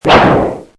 swordlunge.mp3